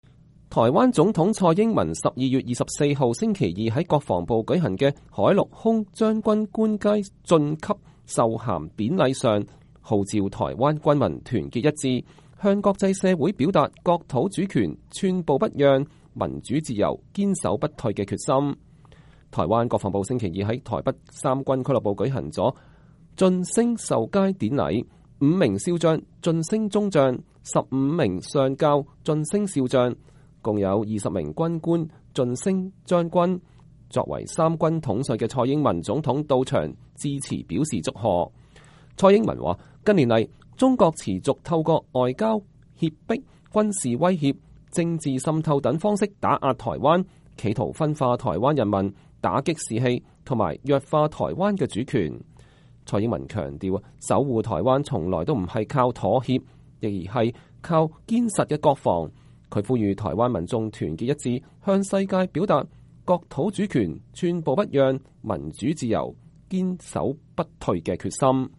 台灣總統蔡英文12月24日（星期二）在國防部舉行的陸海空軍將官晉級授銜典禮上號召台灣軍民團結一致，向國際表達“國土主權、寸步不讓；民主自由、堅守不退”的決心。
蔡英文在授銜賀詞結束後到台下和20位晉升將官逐一握手祝賀，她隨後又跟在場的軍官家屬逐一握手致意，隨後離開現場，全體軍人用熱烈的掌聲歡送蔡英文總統。